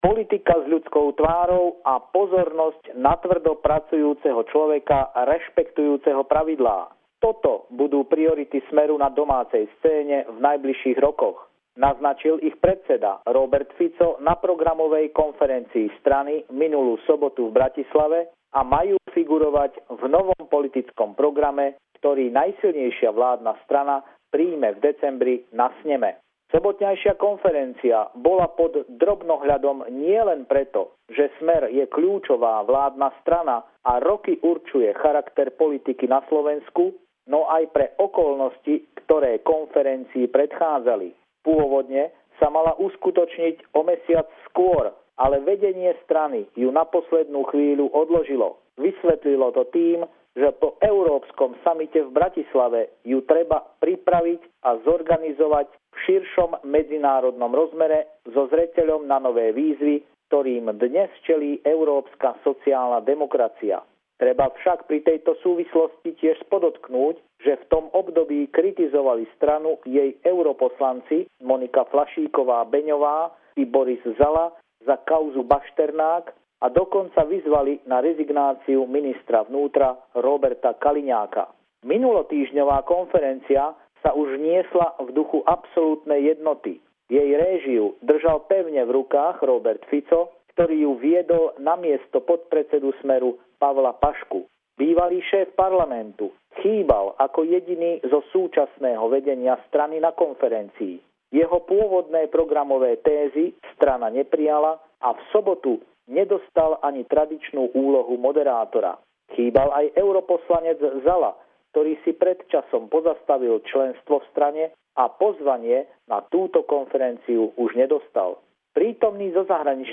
Pravidelný telefonát týždňa